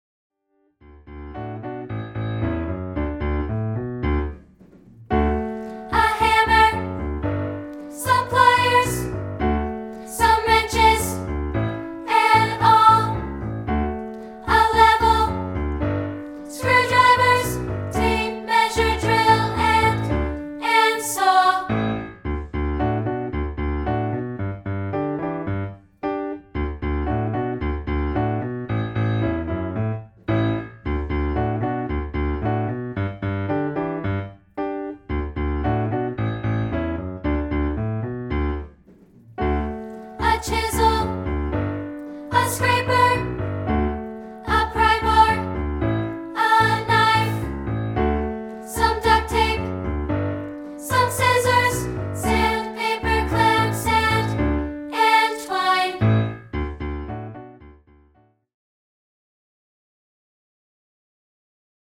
Background Vocals (High Part)